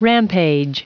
Prononciation du mot rampage en anglais (fichier audio)
Prononciation du mot : rampage